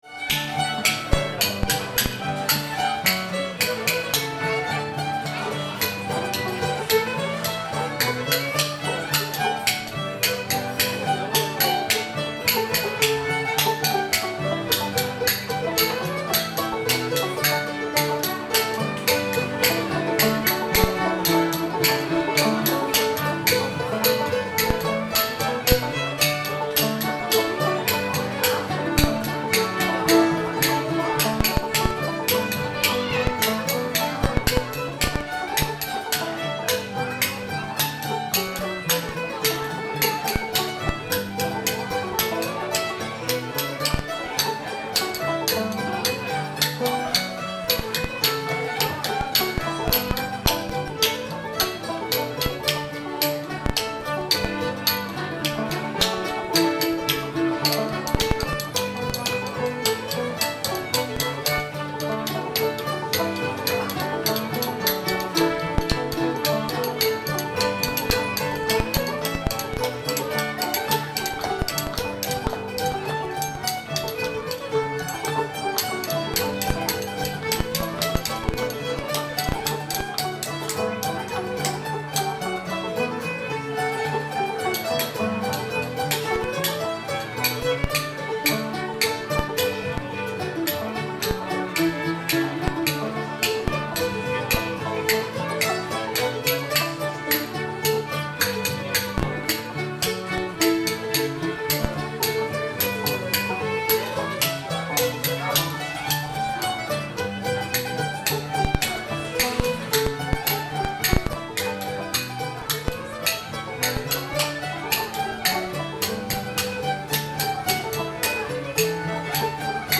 needlecase [D]